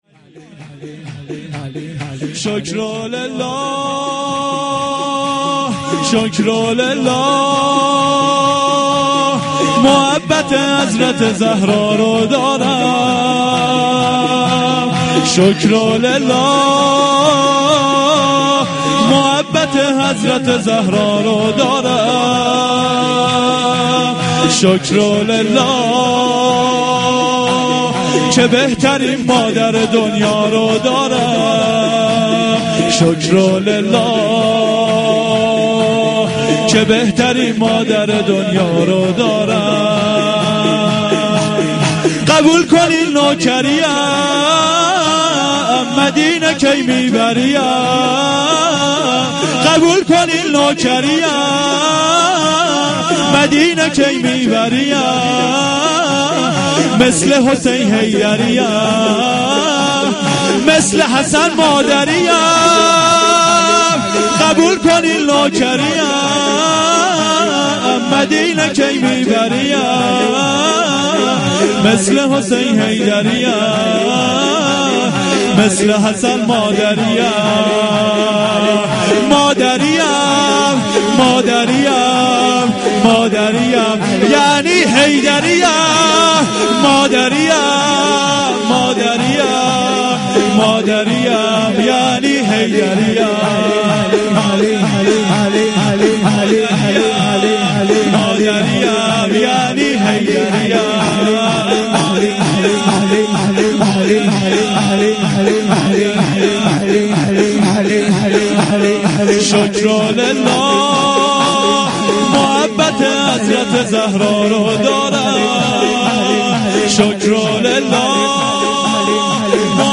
veladate-hazrate-zahra-s-93-soroud-part5.mp3